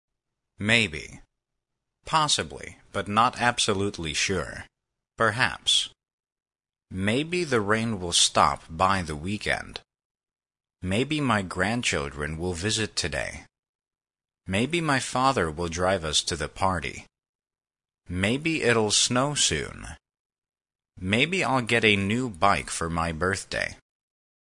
maybe (adv): possibly, but not absolutely sure; perhaps < previous > next Listen to the Lesson | Listen with pause Example sentences: " Maybe the rain will stop by the weekend.